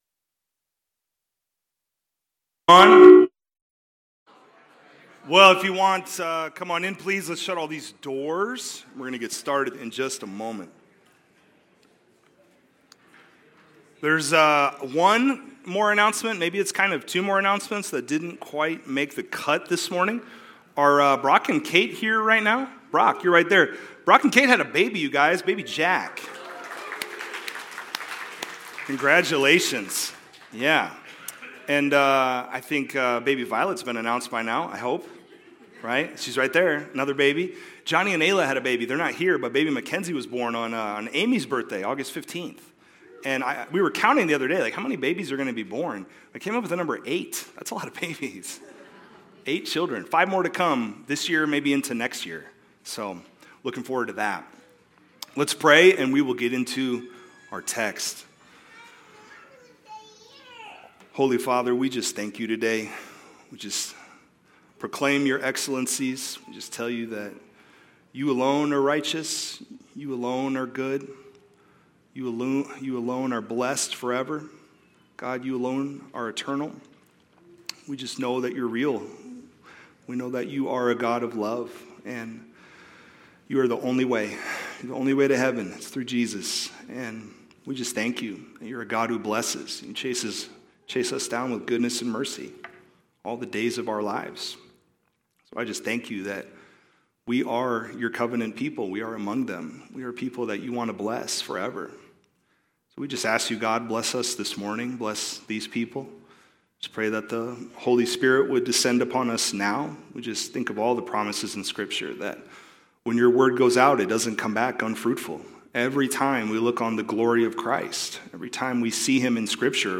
United By Faith To The Suffering Of Christ - New Life Community Church Marion, IA